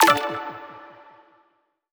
button-direct-select.wav